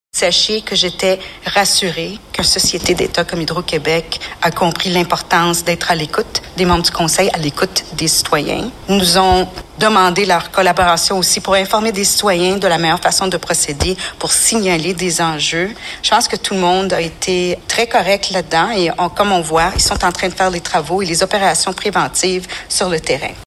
La mairesse Doreen Assaad a fait le point, mardi soir, sur la situation des pannes d’électricité récurrentes à Brossard.